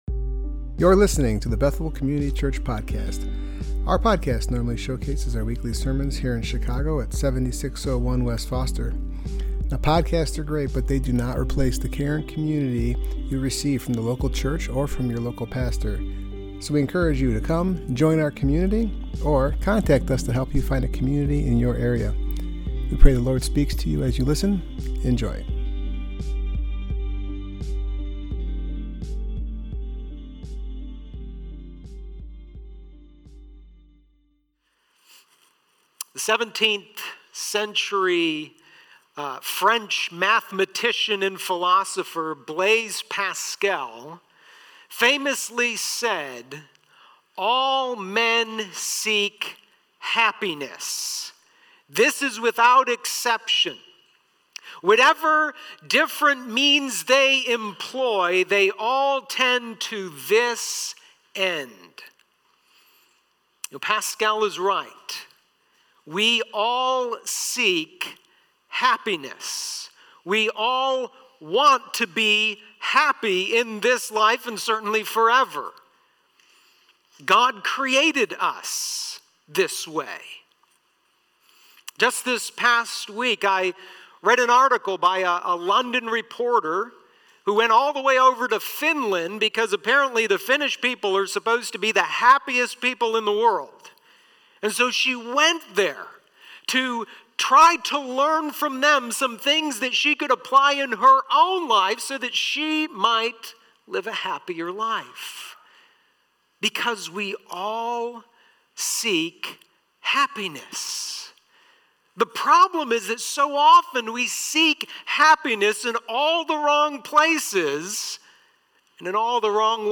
Passage: Psalm 1 Service Type: Worship Gathering